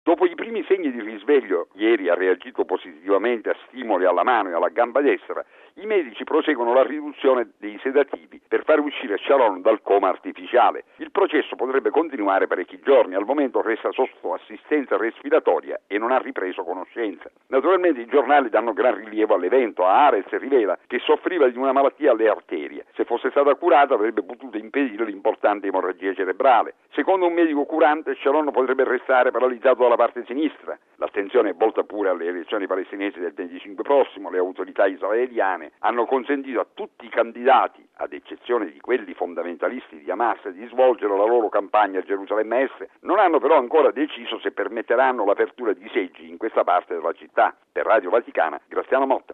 (10 gennaio 2006 - RV) Restano critiche ma stabili le condizioni di salute del premier israeliano Sharon, ricoverato all'ospedale Hadassah di Gerusalemme dopo le gravi emorragie cerebrali della settimana scorsa. Il servizio